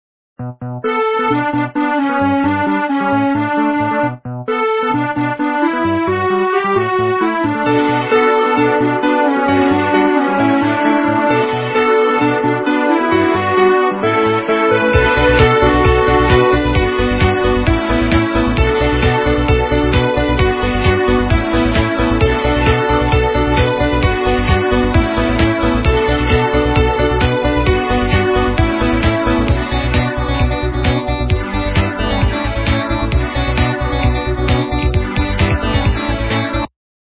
- западная эстрада
полифоническую мелодию